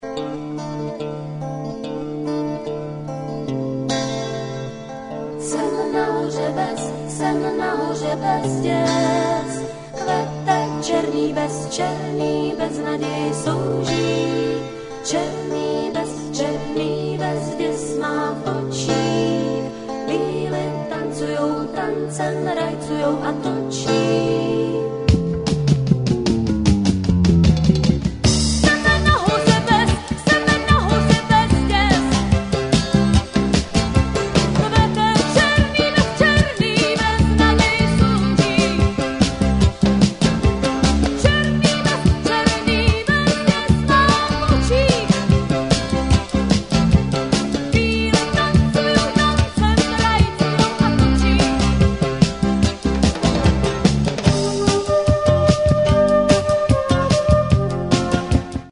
dívčí novovlnná skupina